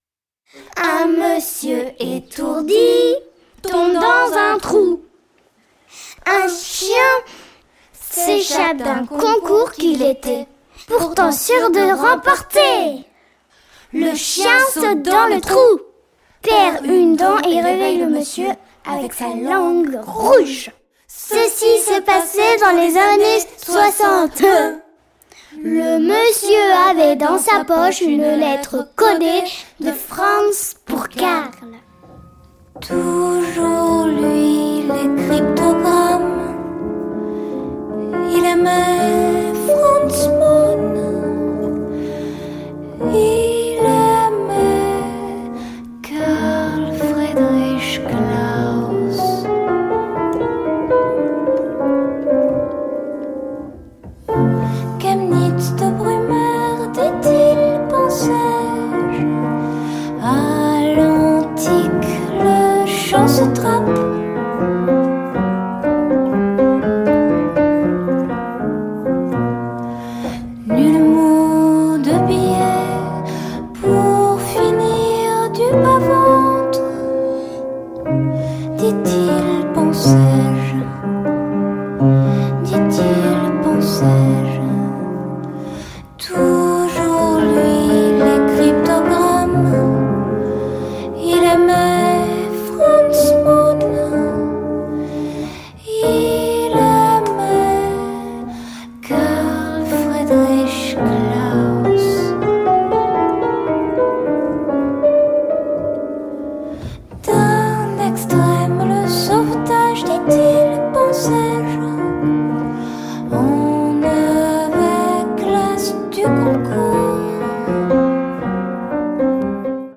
民族音楽やクラッシック等の要素を取り入れた傑作！
フックの効いたキャッチーなメロディと、少ない音数で多彩な響きを与えてくれるアンサンブルがとにかく素晴らしいですね！